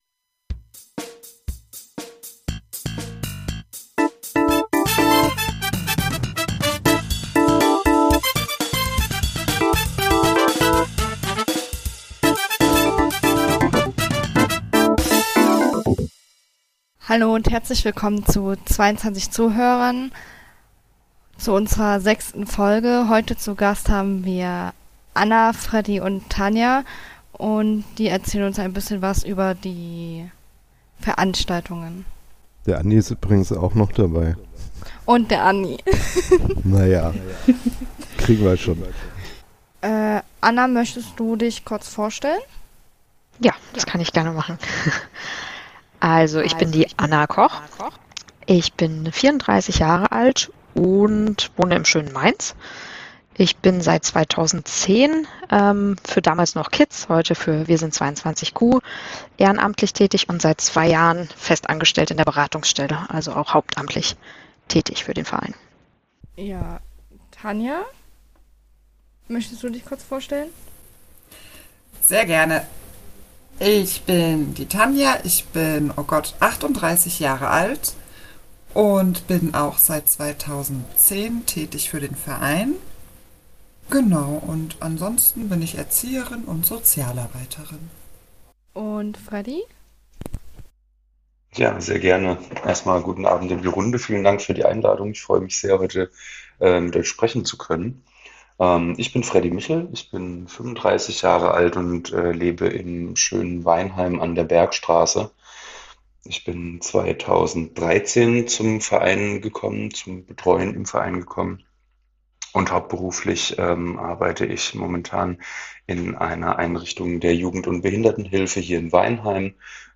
Ein Gespräch mit drei Leitungen